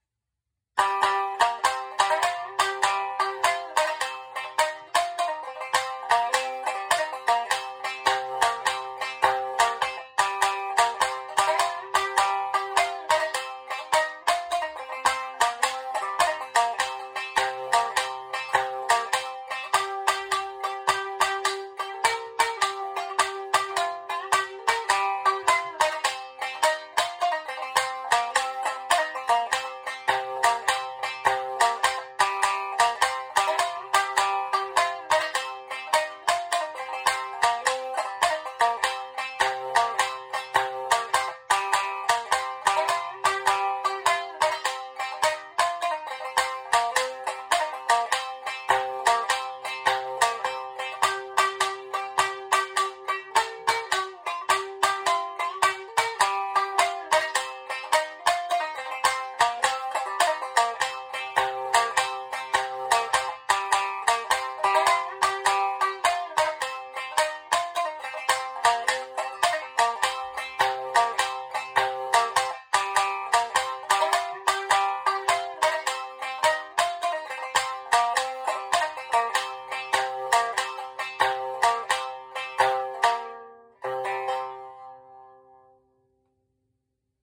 楽曲、奏法ともに特徴的な津軽三味線ですが、実は音数が少なくゆったりとしたテンポで、いわゆる民謡の曲としてイメージされやすいような楽曲も数多くあります。
三味線による伴奏部分のみとなりますが、演奏を録音した音声ファイルも用意してありますので、お時間がありましたらぜひ聞いてみてください。
今回紹介したような曲はゆったりとしていて複雑なフレーズもないので、津軽三味線を始めて最初に練習する曲として採用されることも多いのですが、